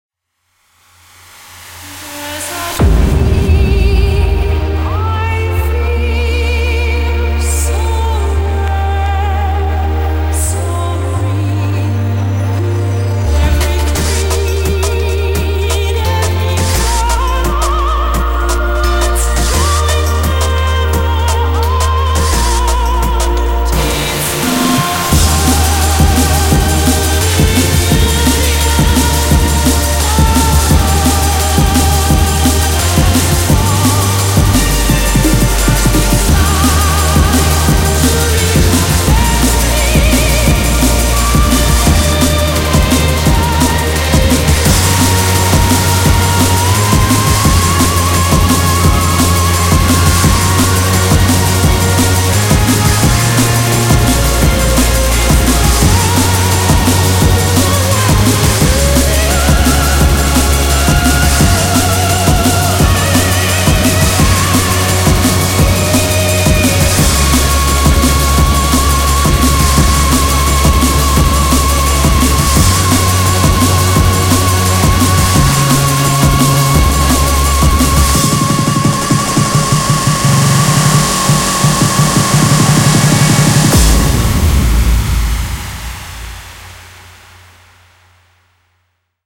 BPM86-172
Audio QualityPerfect (High Quality)